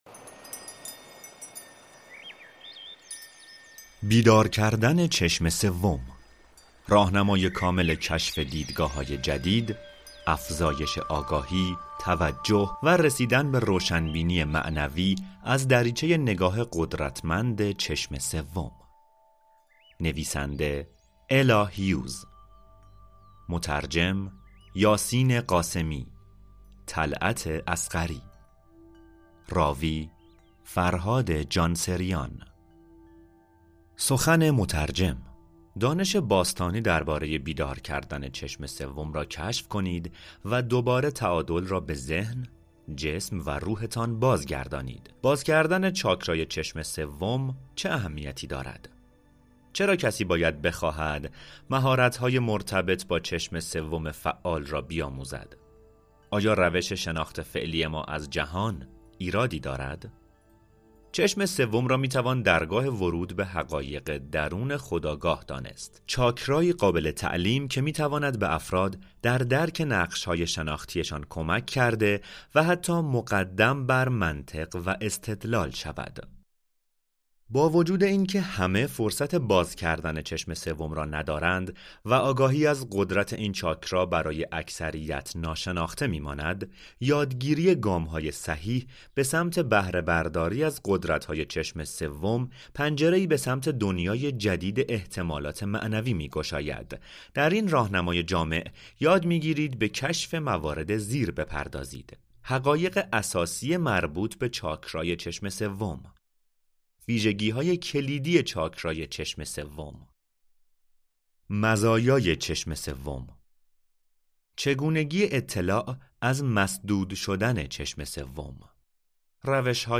کتاب صوتی بیدار کردن چشم سوم
third-eye-awakening-podcast.mp3